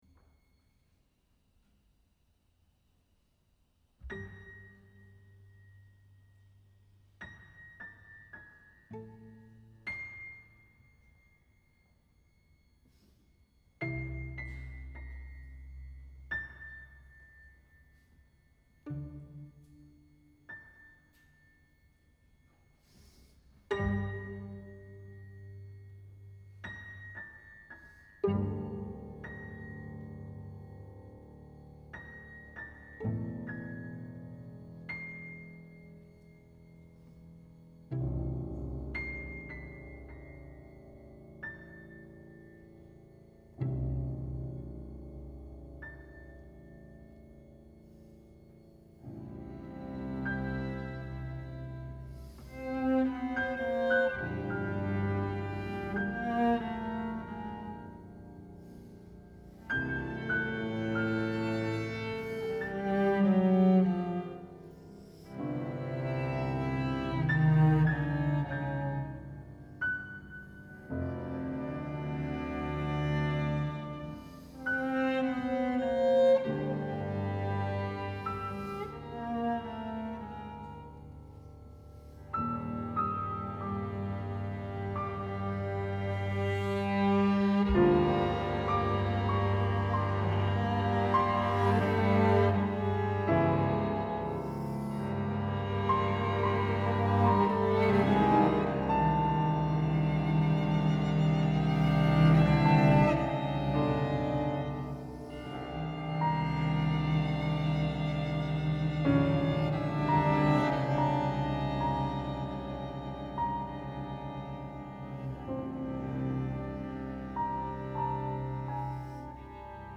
mit leisen Flügelschlägen niederwärts
für Klaviertrio